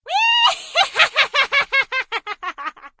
witch.ogg